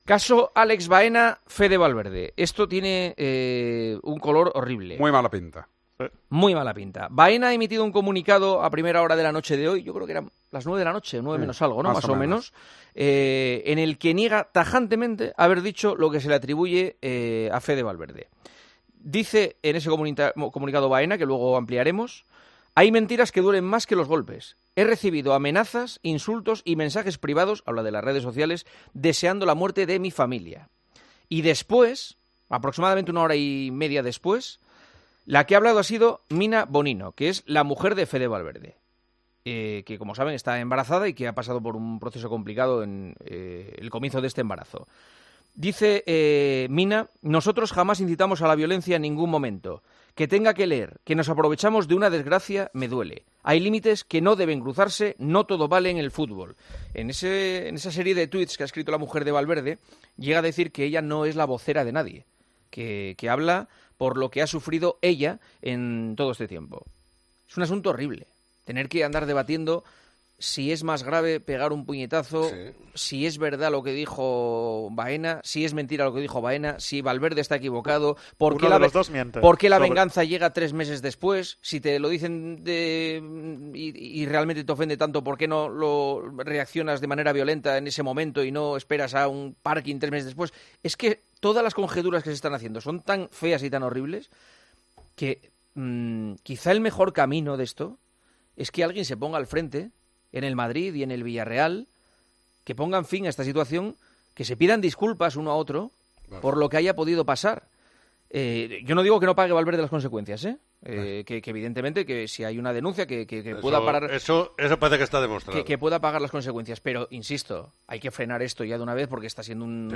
El director de El Partidazo de COPE, por segundo día consecutivo, se refiere a la polémica por la agresión de Valverde a Baena, en un día de comunicados: "Tiene un color horrible".